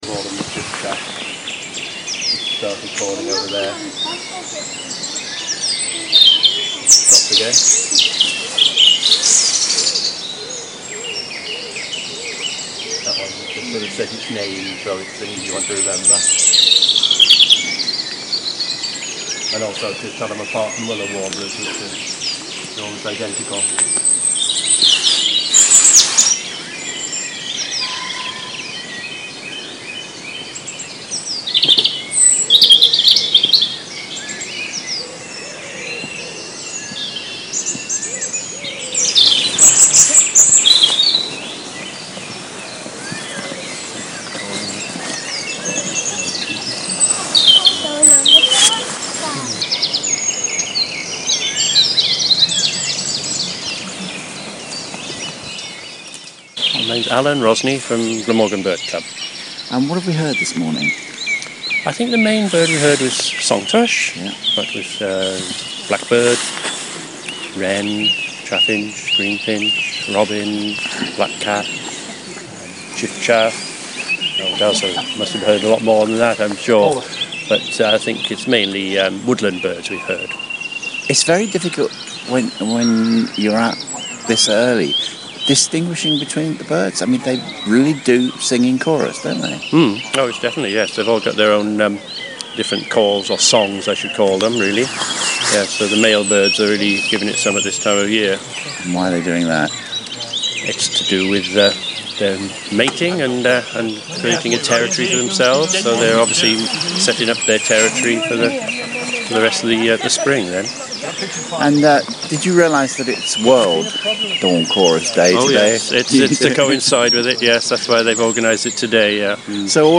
World Dawn Chorus Day at Dyffryn Gardens, Wales
Up at 4am to hear to chorus of birds at the National Trust's Dyffryn Gardens, Glamorgan South Wales.